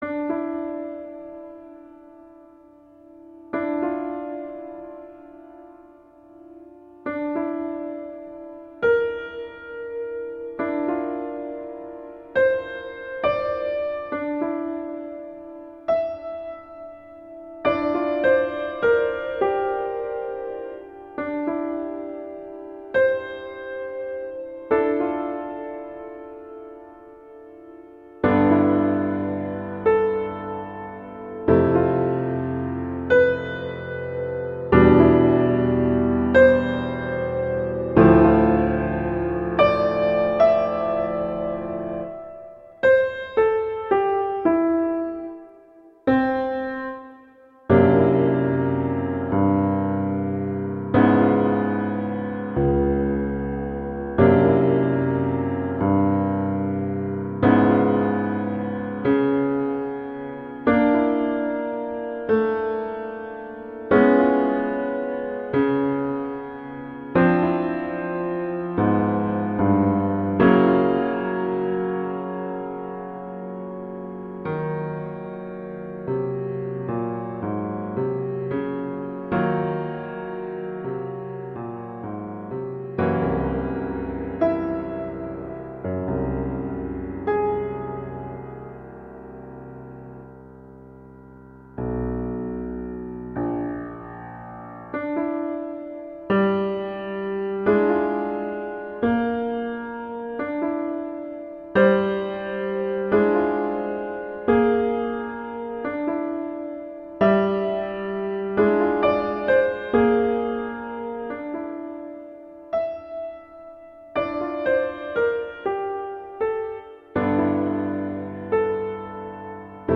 Instrumentation: piano solo
classical, french